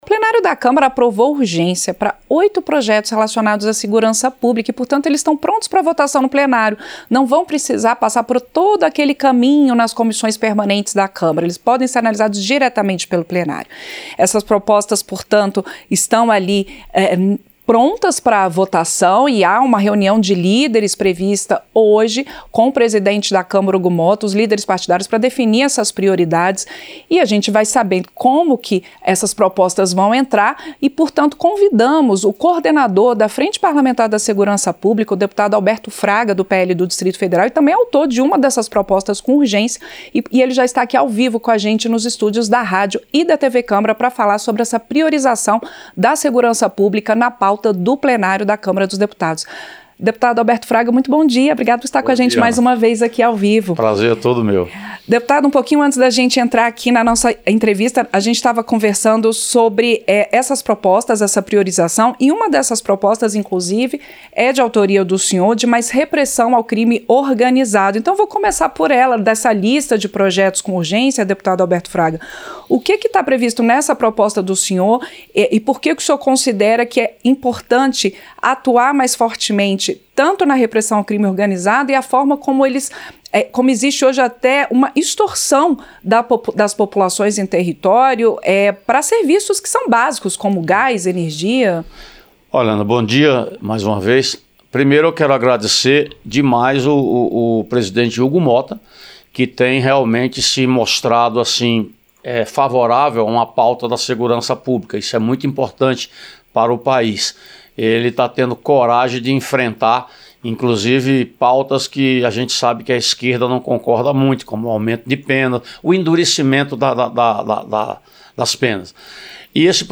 Entrevista - Dep. Alberto Fraga (PL-DF)